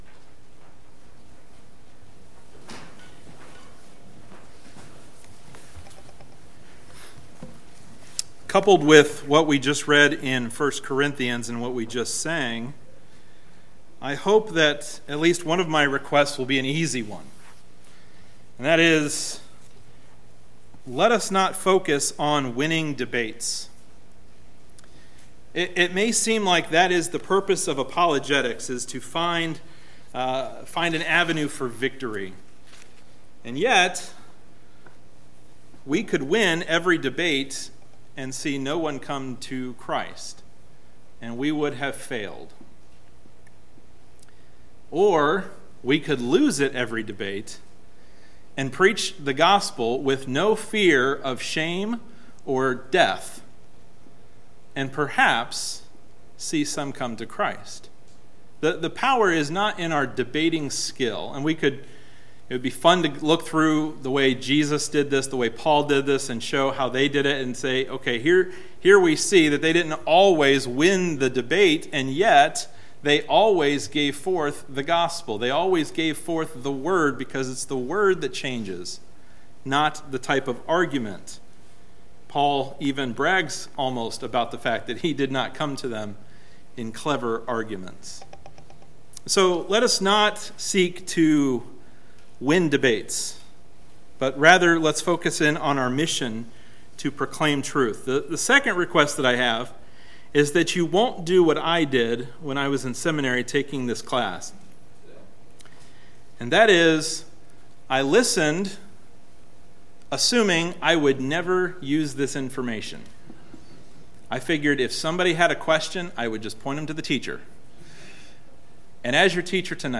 Service Sunday Evening